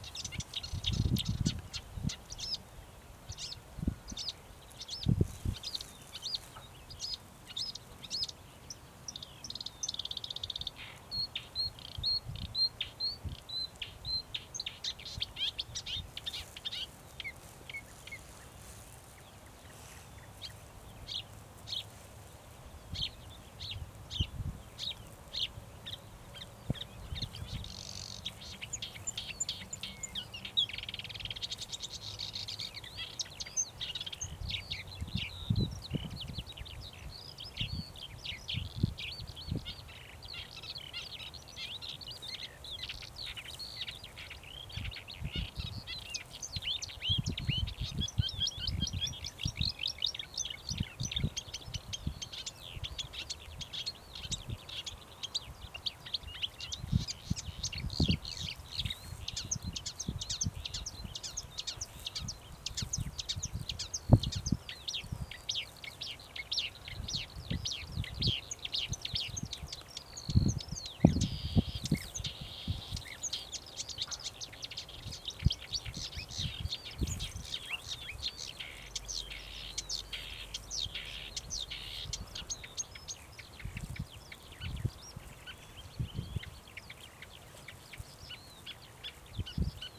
болотная камышевка, Acrocephalus palustris
СтатусПоёт